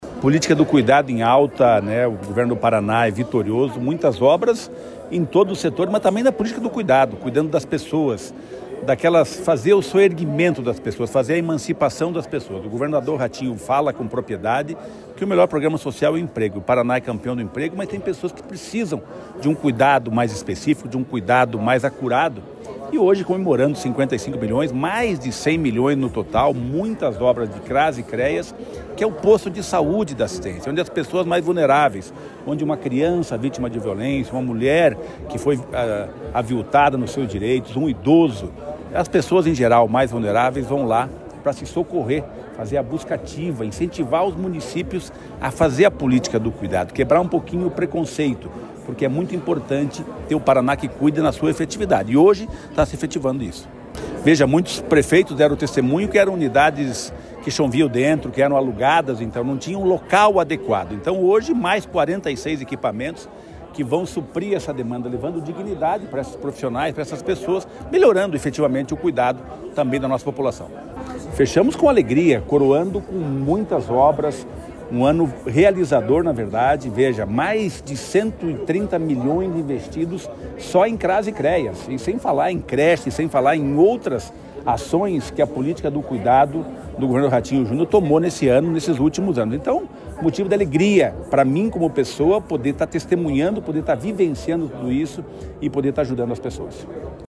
Sonora do secretário Estadual do Desenvolvimento Social e Família, Rogério Carboni, sobre o repasse aos municípios para novos Cras e Creas